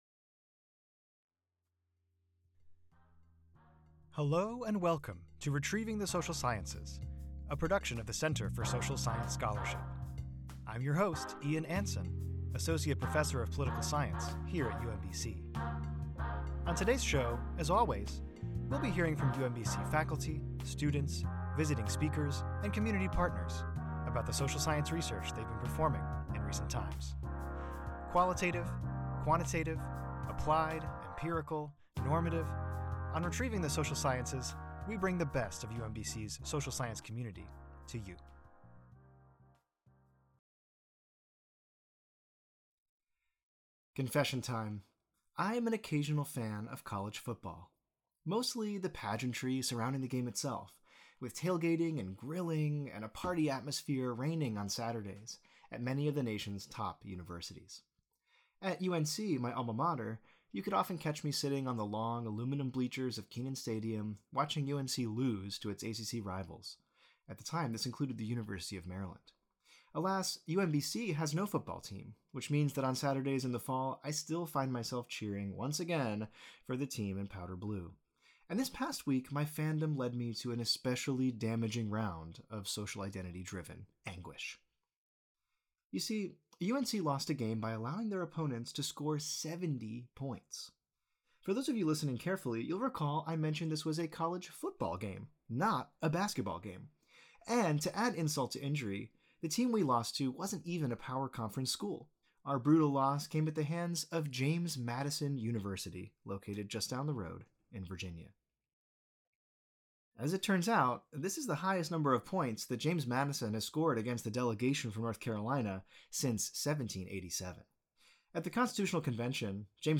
On today's episode we hear a rebroadcast of the 2024 UMBC Constitution Day Lecture, sponsored by UMBC's Center for Social Science Scholarship and the UMBC Department of Political Science. This year's lecture was delivered by Jared DeMarinis, the Maryland State Administrator of Elections.